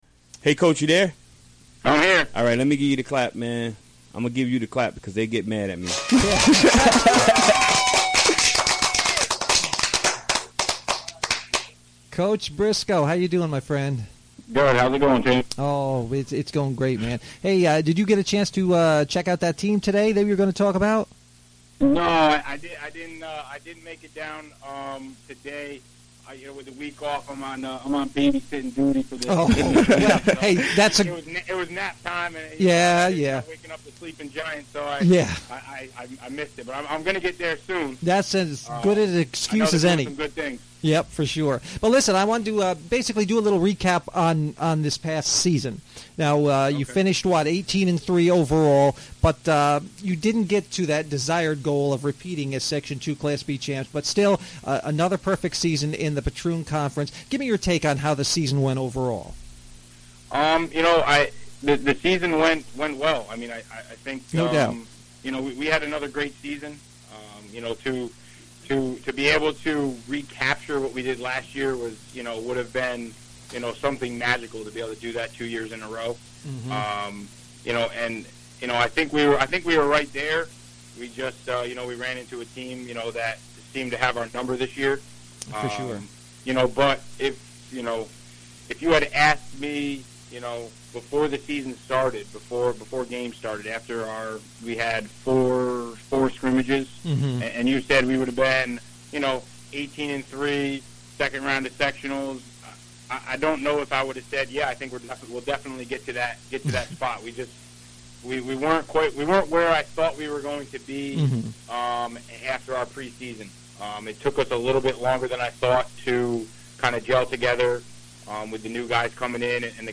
Recorded during the WGXC Afternoon Show Wednesday, April 19, 2017.